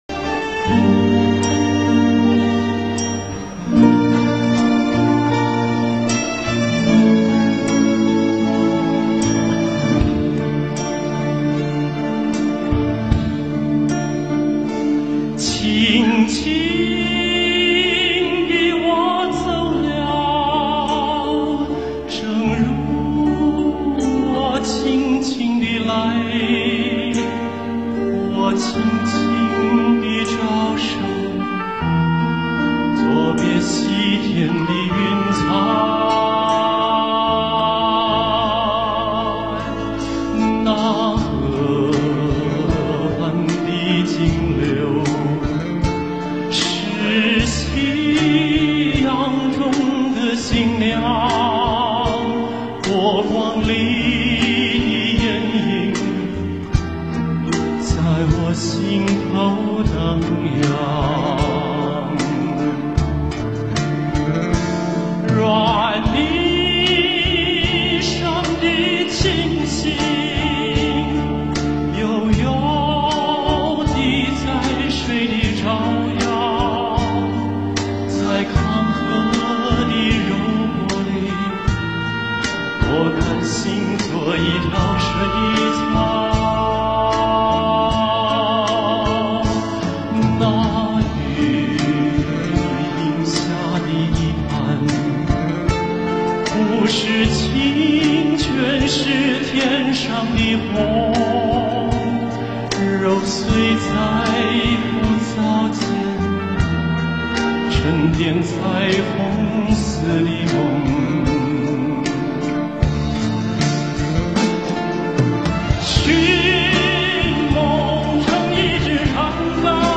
他的声音纯净、高亢，感染力强，得到许多人的喜爱。
他磁性般的音质很醇厚，歌曲都很有哲理，听了让人难以忘怀。